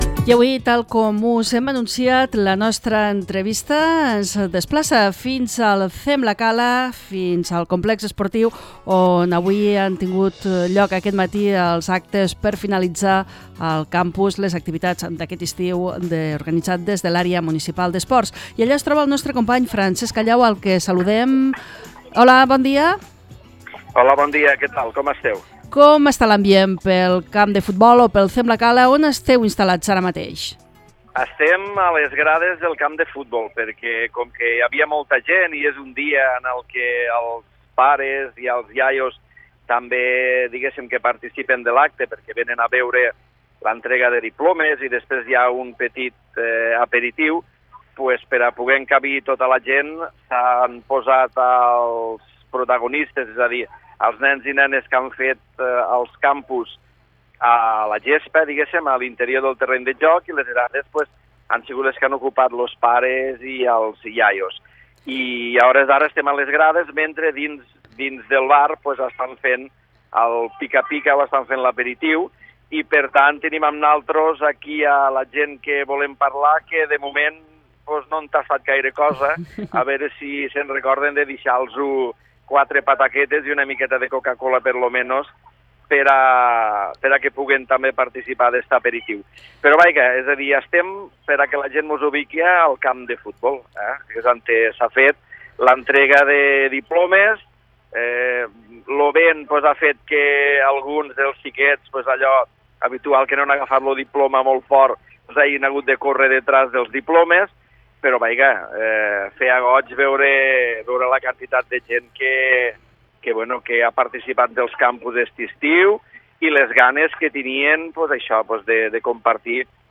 L'entrevista - Cloenda dels Summer & Sports Camps 2017
Avui ens hem traslladat a les instal·lacions esportives municipals on ha tingut lloc l'acte de cloenda del Summer & Sports Camps 2017, el campus d'estiu que s'ha dut a terme els matins dels dies laborables dels mesos de juliol i agost